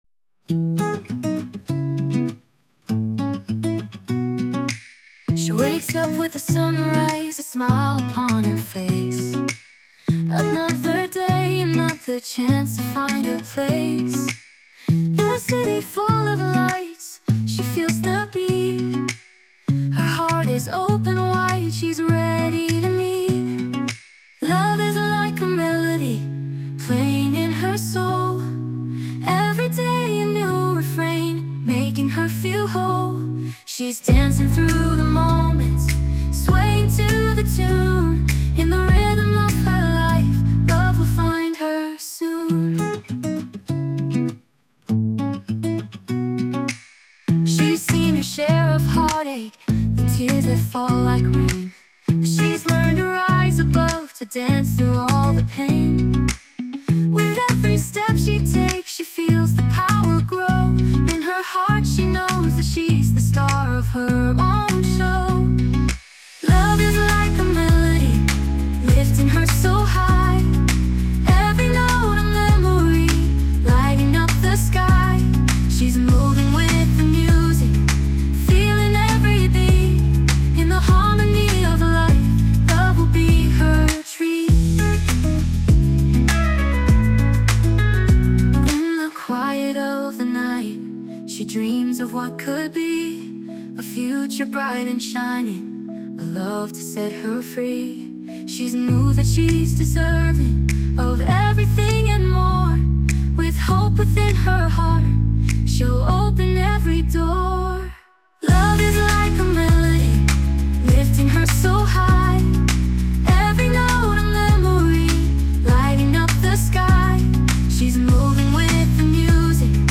洋楽女性ボーカル著作権フリーBGM ボーカル
女性ボーカル曲（英語）です。